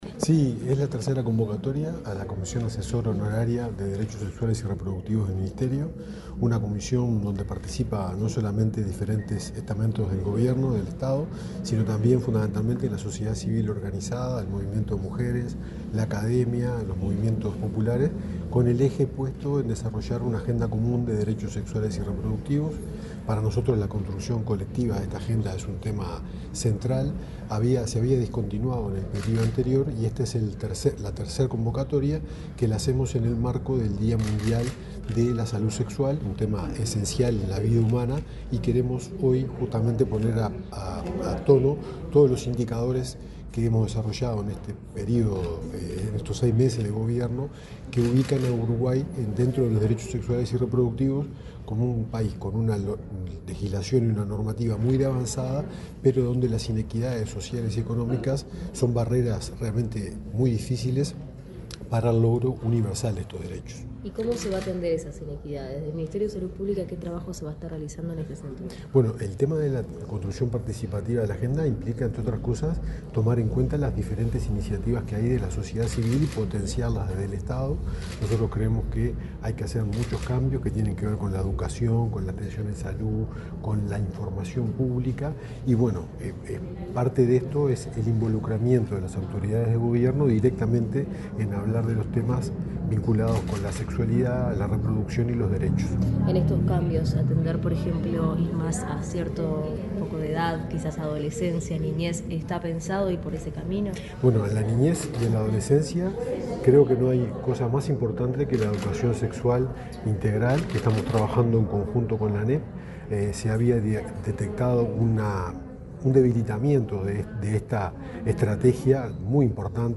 Declaraciones del subsecretario de Salud Pública, Leonel Briozzo